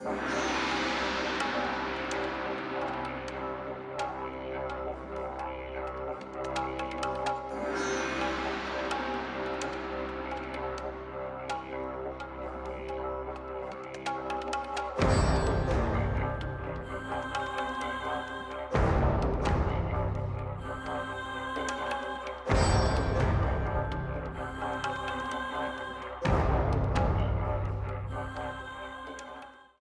A purple streamer theme
Ripped from the game
clipped to 30 seconds and applied fade-out